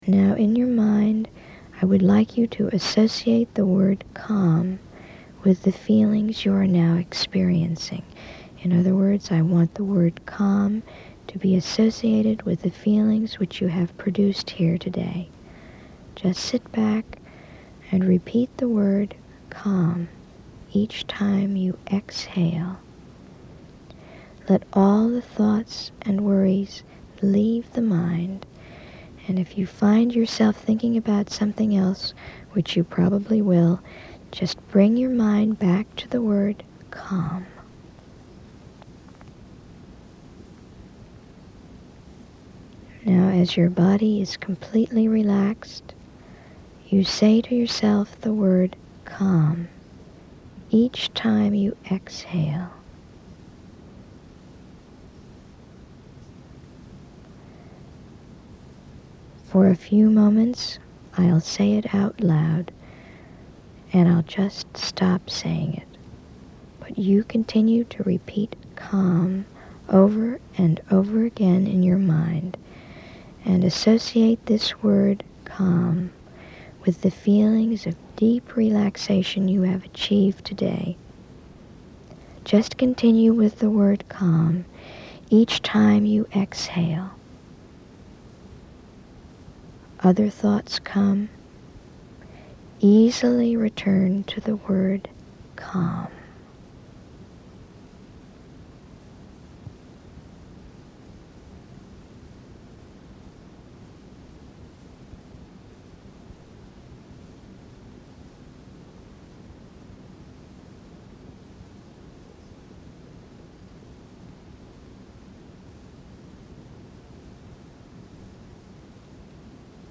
Self Relaxation Exercises
Part Three: Autogenic Training (8:54)